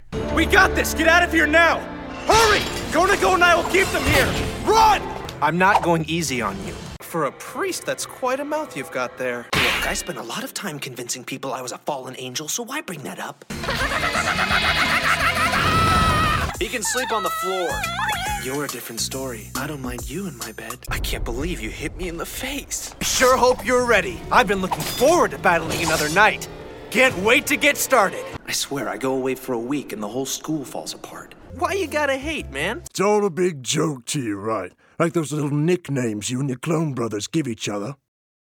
Animation
• Accents/Dialects
• Character Voices